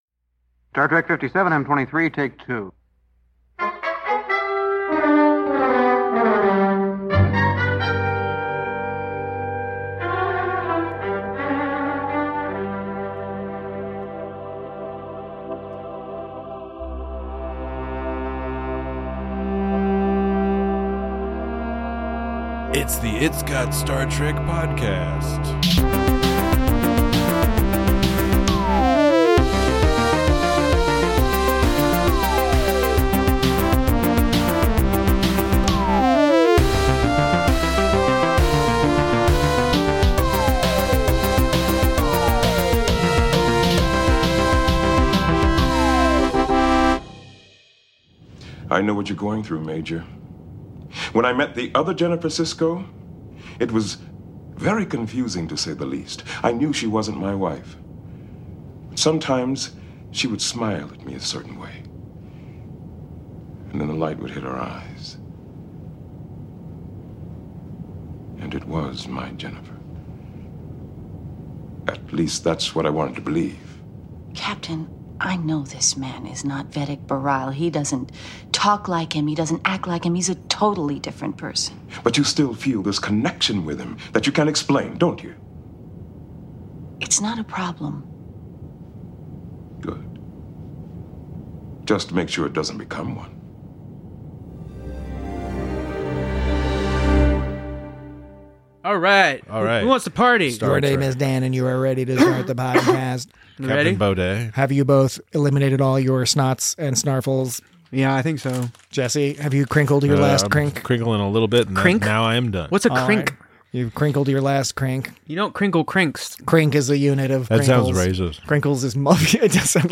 Join your weary hosts as they discuss how a mirror universe episode that explores the ethics and morality of romantic entanglements with alternate versions of dead lovers could come out so mind-dullingly boring!